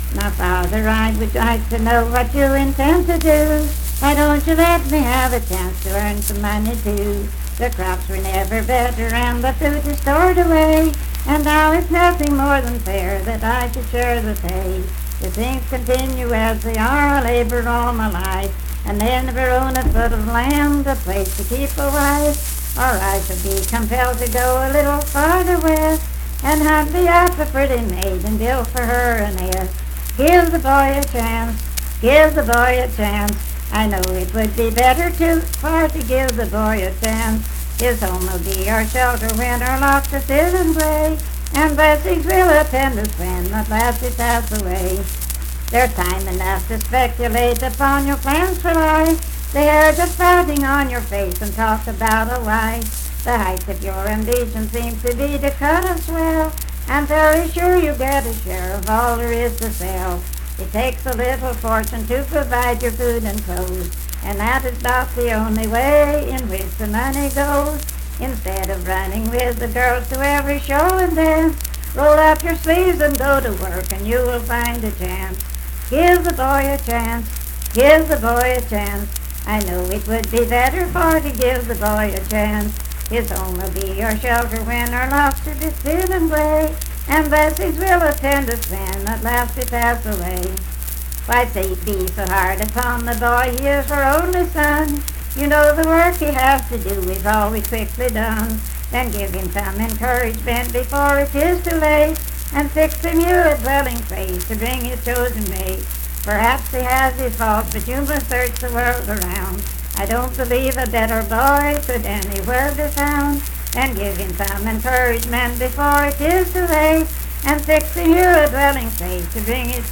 Unaccompanied vocal music performance
Miscellaneous--Musical
Voice (sung)